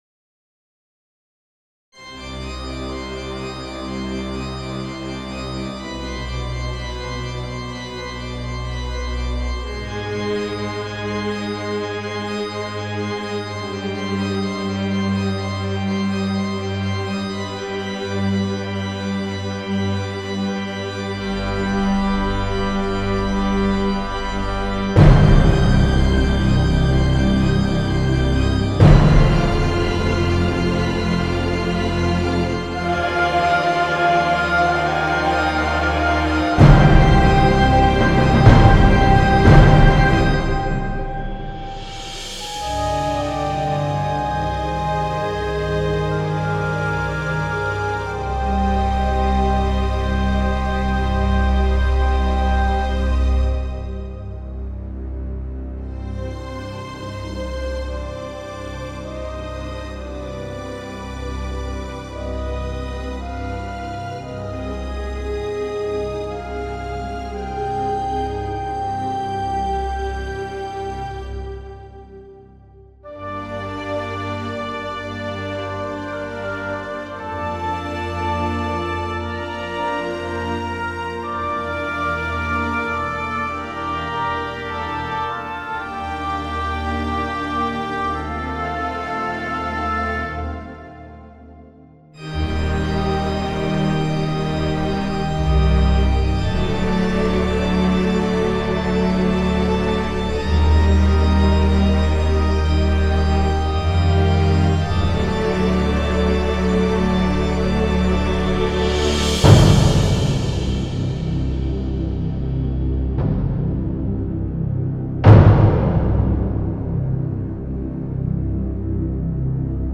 Fully re realized into a modern more sleek and atmospheric orchestral track.